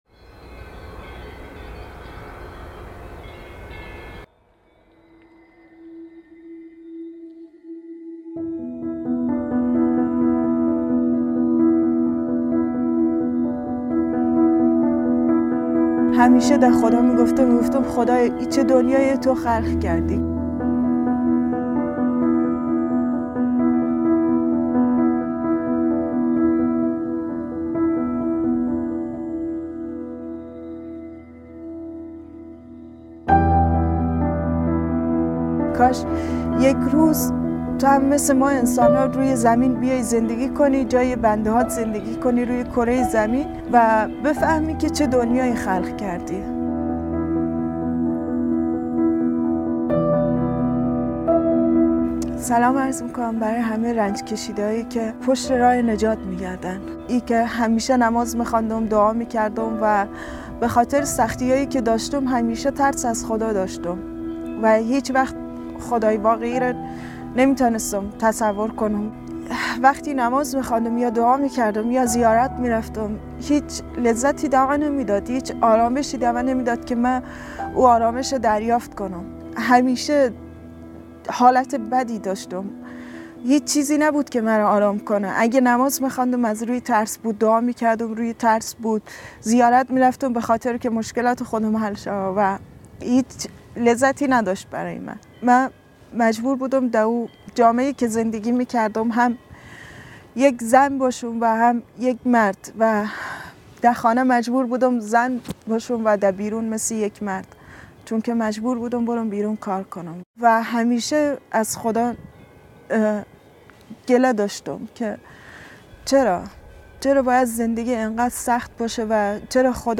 خواهر افغان داستان نجات و شفای خود را بيان می‌كند.